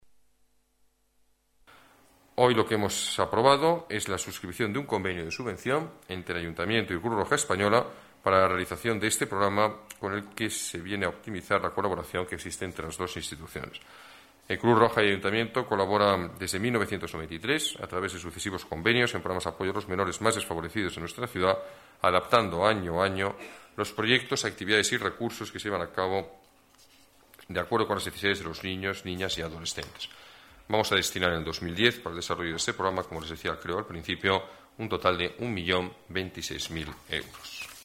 Nueva ventana:Declaraciones alcalde, Alberto Ruiz-Gallardón: convenio Cruz Roja, atención escolares después de clase